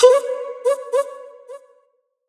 loop (vox).wav